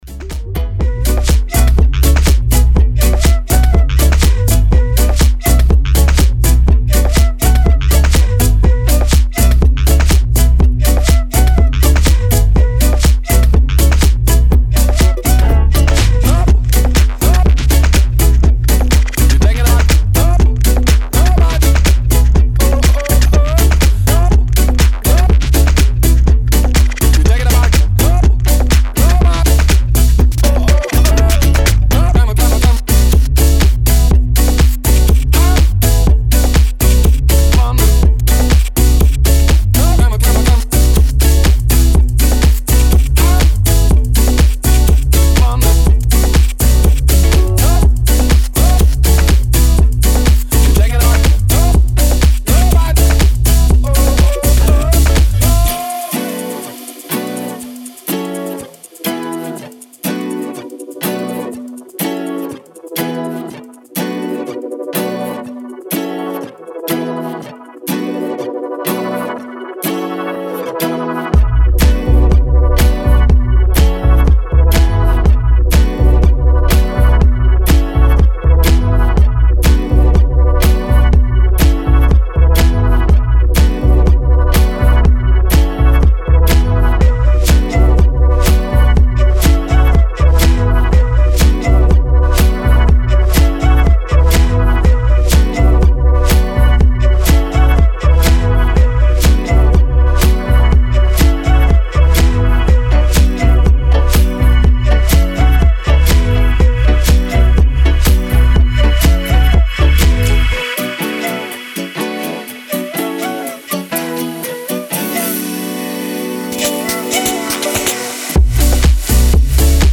Style: House / Tech House